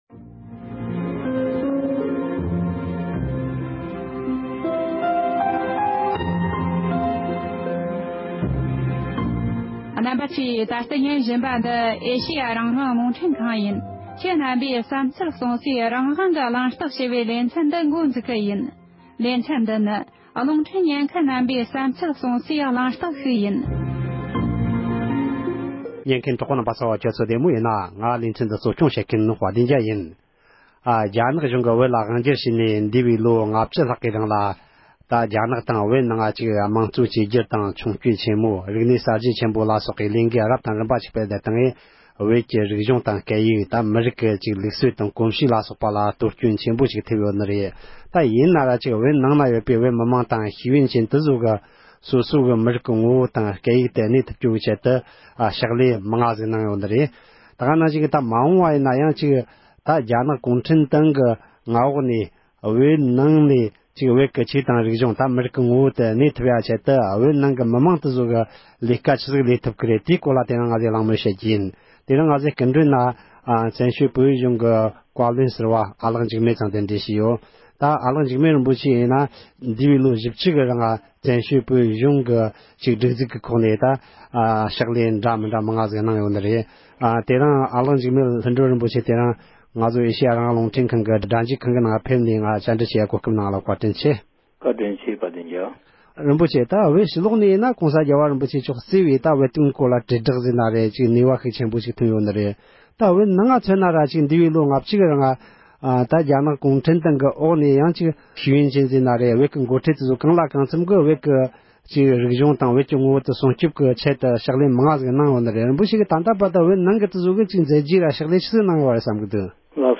གླེང་མོལ་ཞུས་པ་ཞིག་ལ་གསན་རོགས་གནོངས